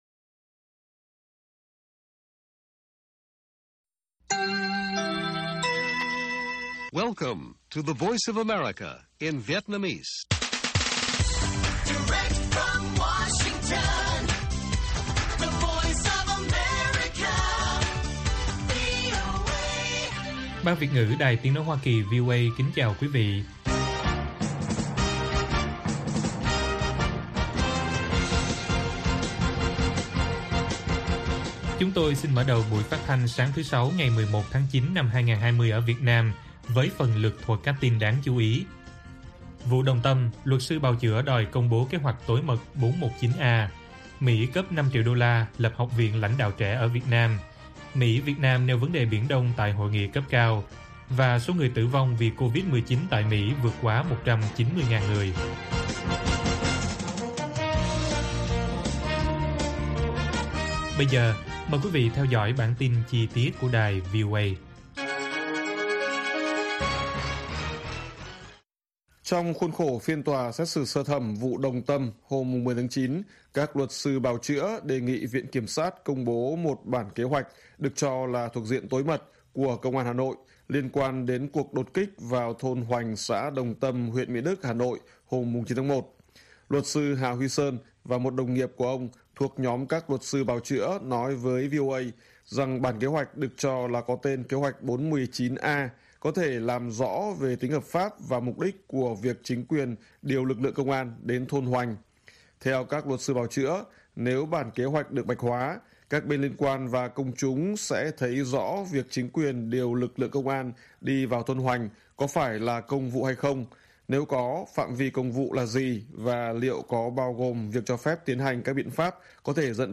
Bản tin VOA ngày 10/9/2020